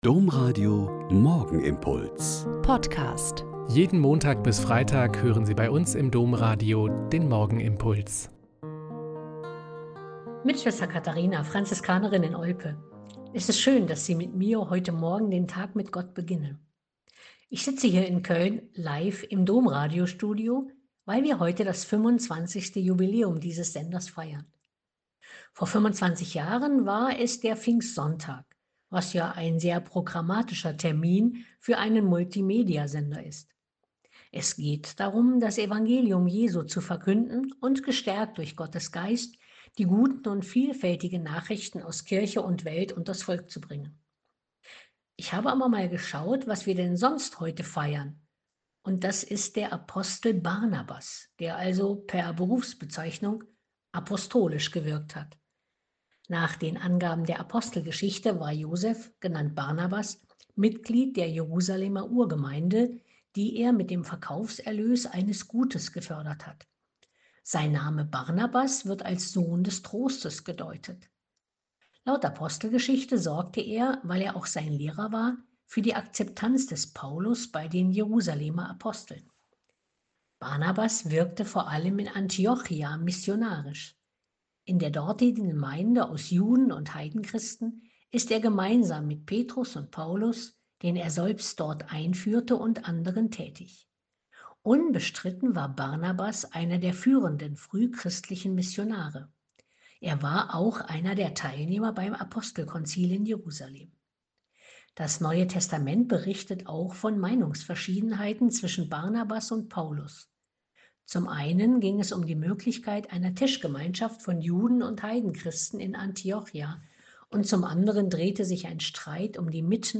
Ich sitze heute in Köln live im Domradiostudio, weil wir an diesem Mittwoch das Jubiläum aus Anlass des 25-jährigen Bestehens dieses Senders feiern.